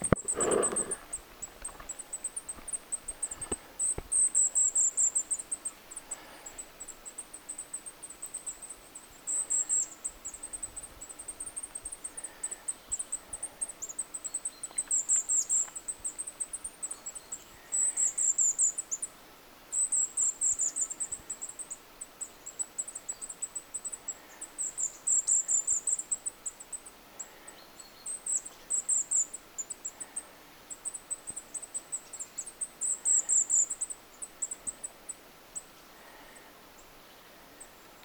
hippiäinen ääntelee ihan lähellä
hippiainen_ihan_lahella.mp3